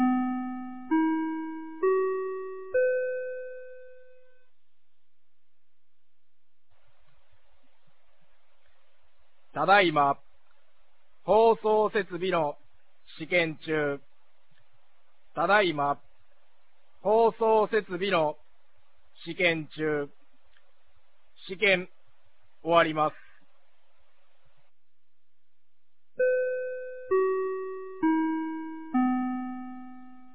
2025年06月28日 16時06分に、美浜町より全地区へ放送がありました。